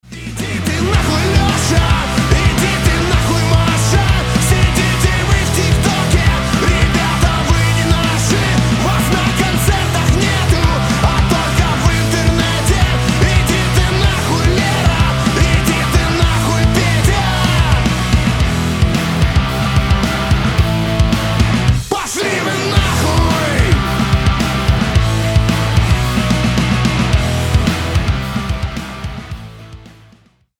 Рок Металл
злые